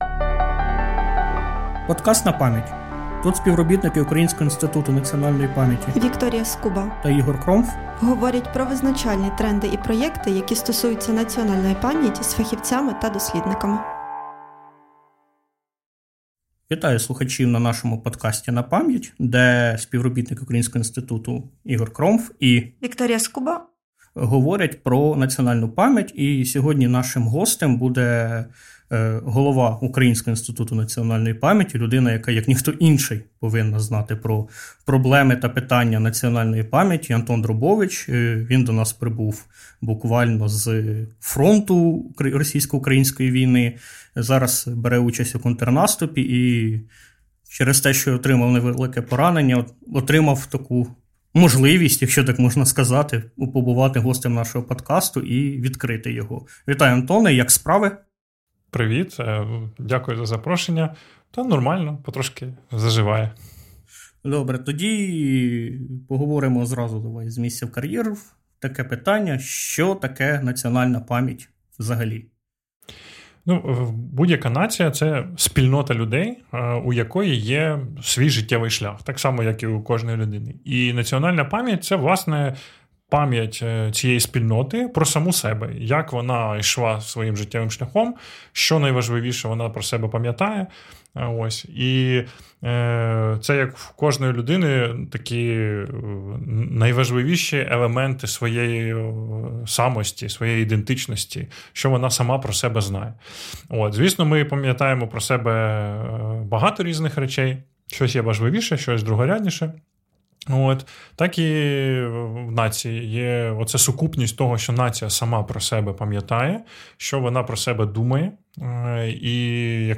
Говоримо з головою Українського інституту національної памʼяті Антоном Дробовичем.